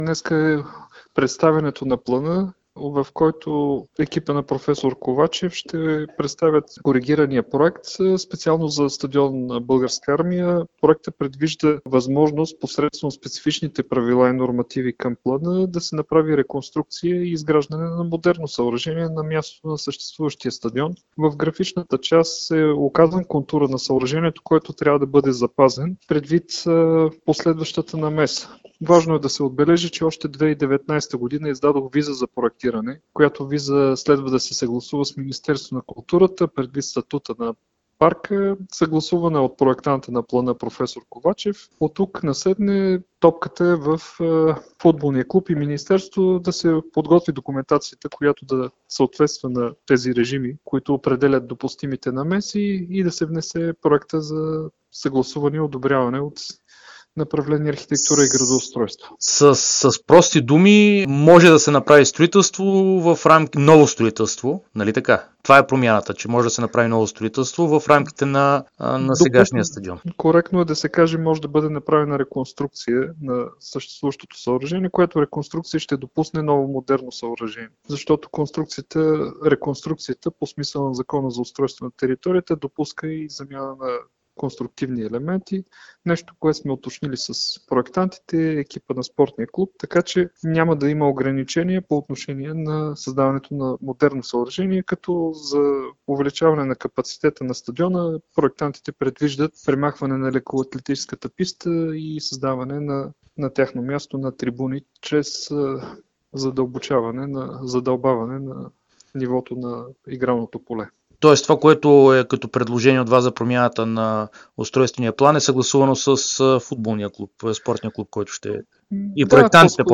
Главният архитект на Столична община арх. Здравко Здравков даде специално интервю пред dsport, в което засегна темата със стадион „Българска армия“, по въпроса с който имаше среща между министъра на спорта Радостин Василев и ръководството на ЦСКА преди няколко дни.